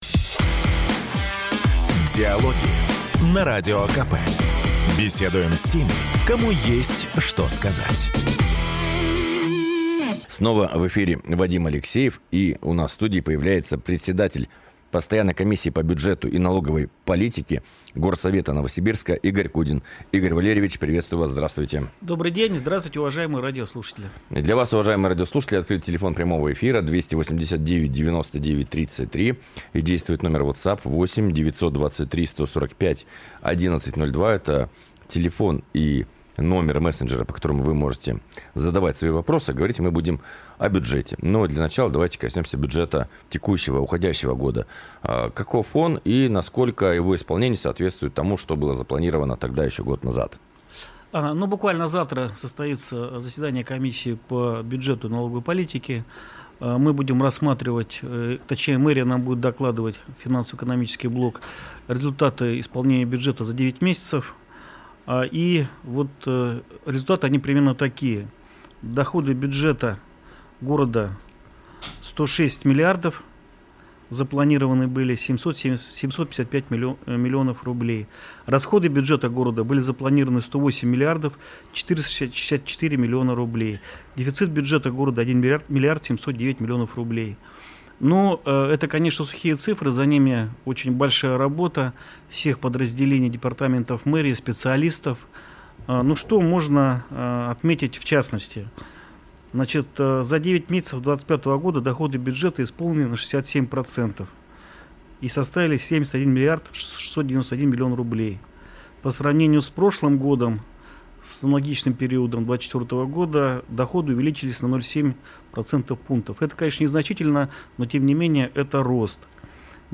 Запись программы, транслированной радио "Комсомольская правда" 27 ноября 2025 года Дата: 27.11.2025 Источник информации: радио "Комсомольская правда" Упомянутые депутаты: Кудин Игорь Валерьевич Аудио: Загрузить